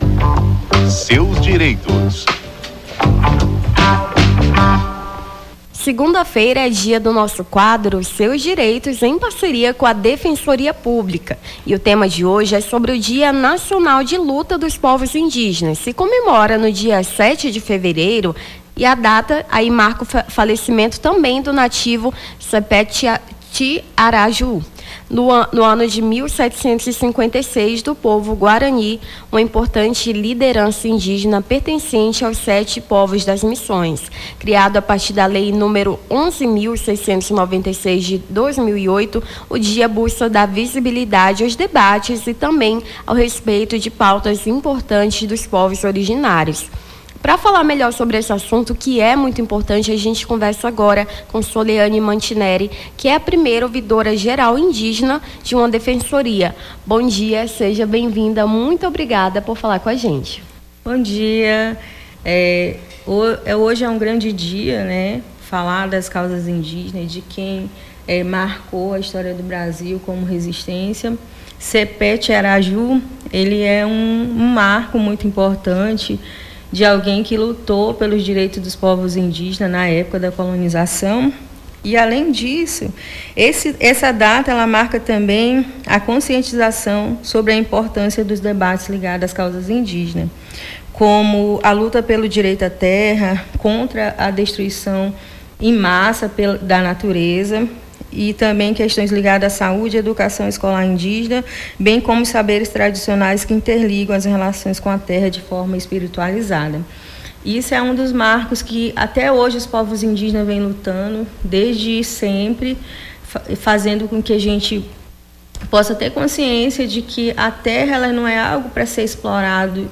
Seus Direitos: ouvidora-geral indígena fala sobre o Dia Nacional de Luta dos Povos Indígenas